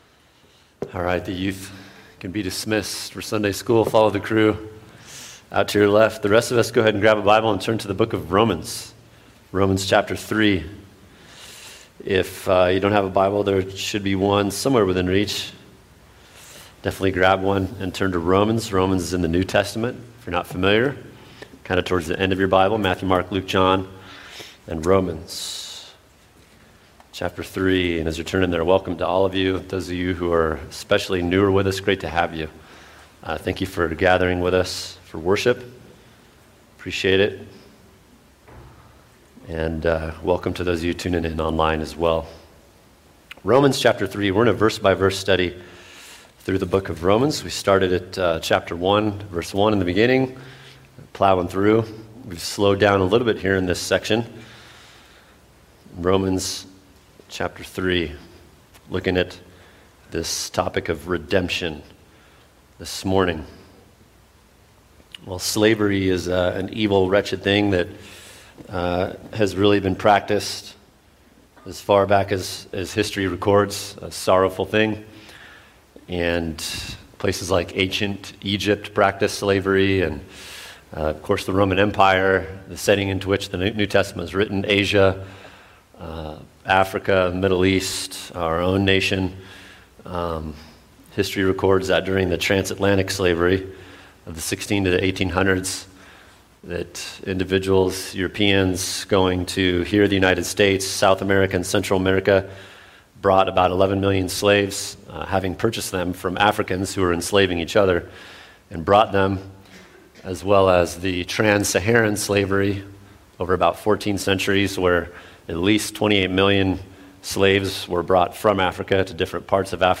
[sermon] Romans 3:24 What is Redemption & Why Do We Need It?